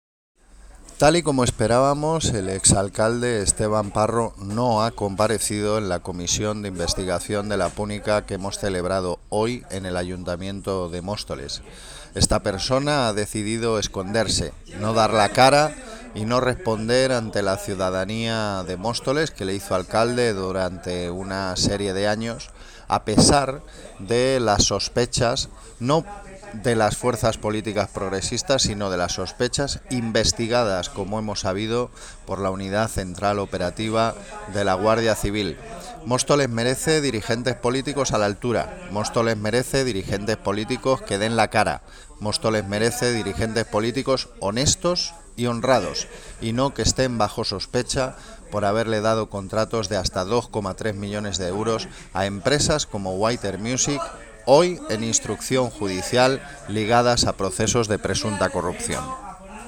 Audio - Gabriel Ortega (Concejal de Cultura, Bienestar Social y Vivienda)